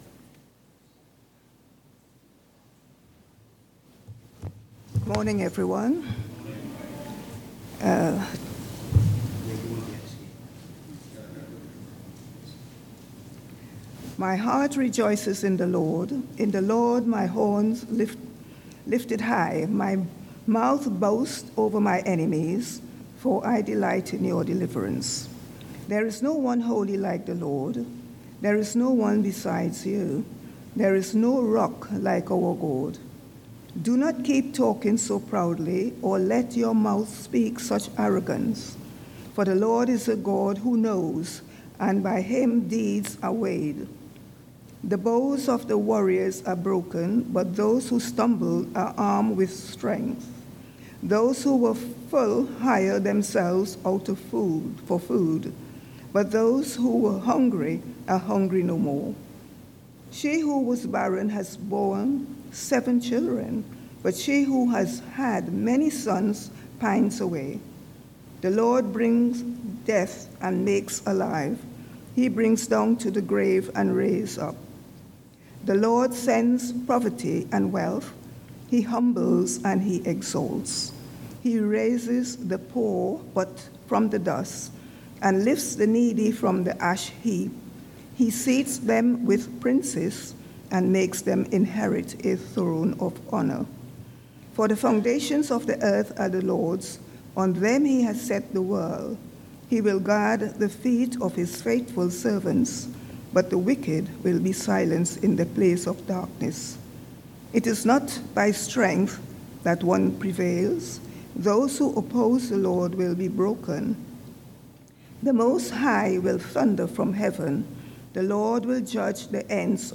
Sunday 16th November Morning Service
1-17 Service Type: Sunday Morning